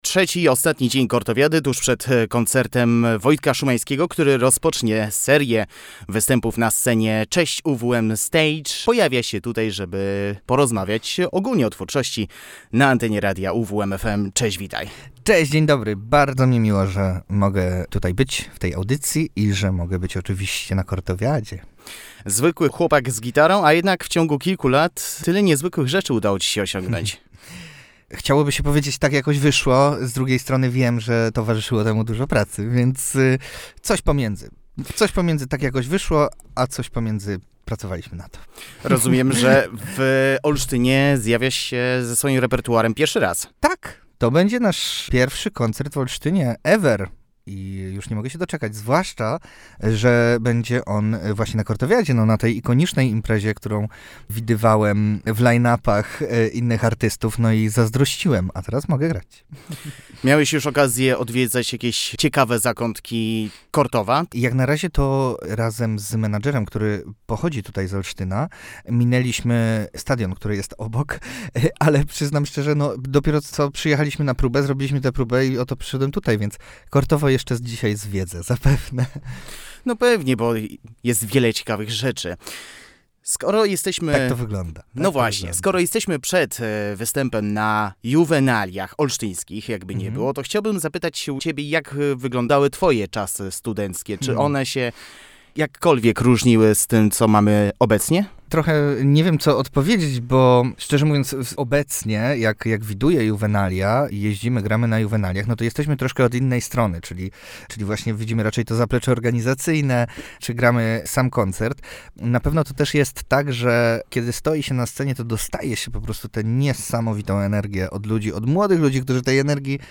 Ja też z wiekiem innymi rzeczami się interesuję, inne rzeczy mnie dotykają” – mówił w naszym studiu.